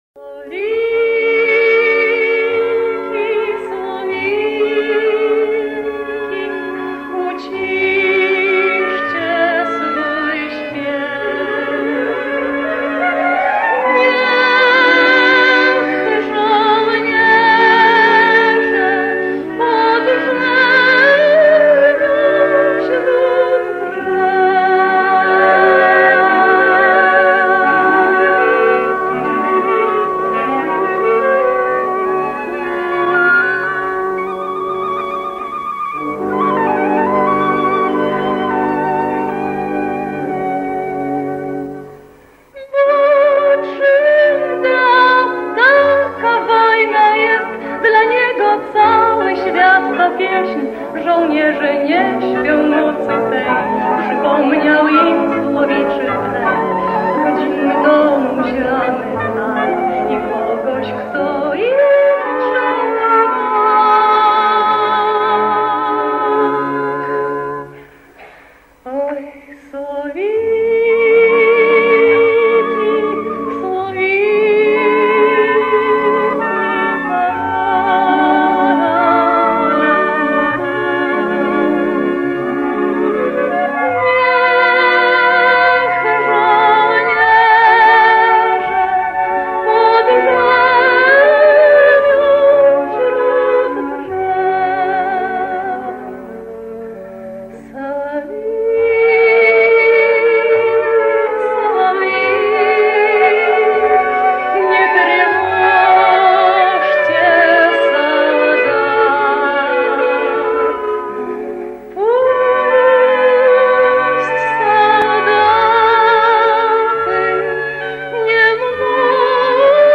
Песня
польской певицы